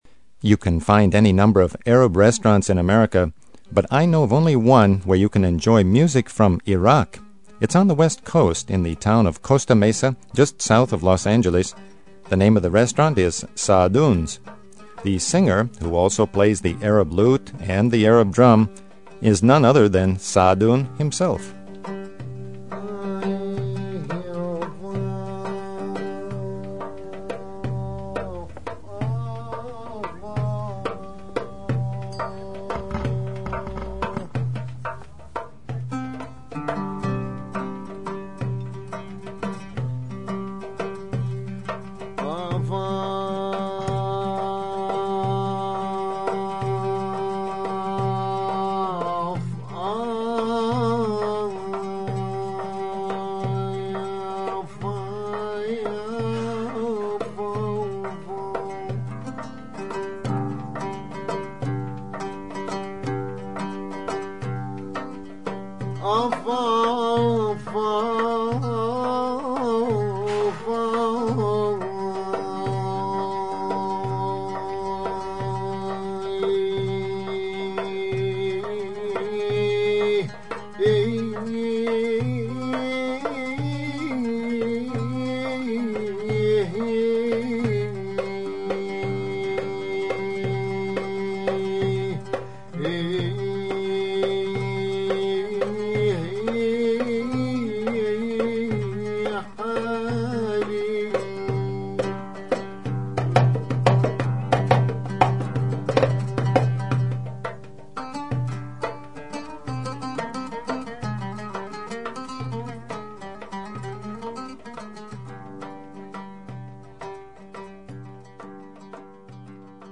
Authentic Iraqi music, live in Costa Mesa, California; classical Palestinian virtuosos in New York